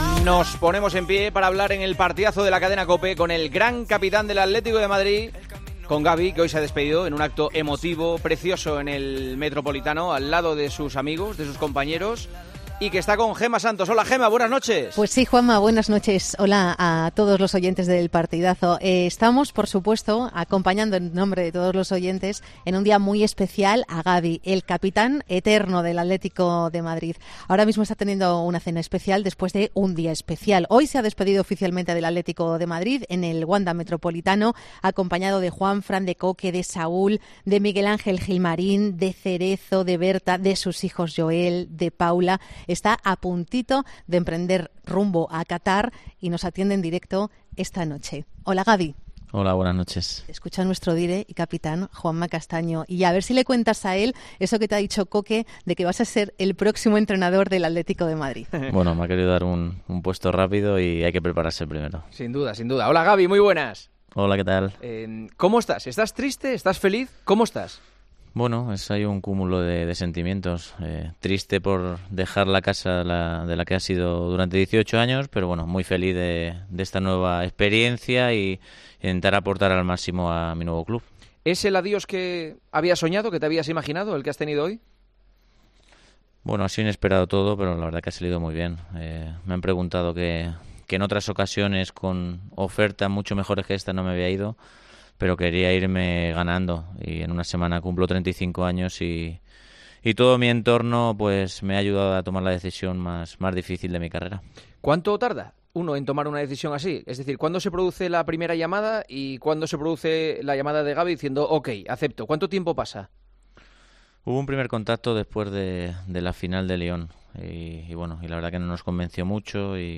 Entrevista en El Partidazo de COPE
Juanma Castaño entrevistó en El Partidazo de COPE al ya ex capitán del Atlético de Madrid, en el día de su despedida, antes de ir a Catar: "Me quedo con tres momentos: cuando ganamos la Liga en el Camp Nou, con nuestra eliminación en el Calderón en Champions ante el Real Madird porque me unió más al Atleti; y mi gol en Lyon, no sabía que sería mi último".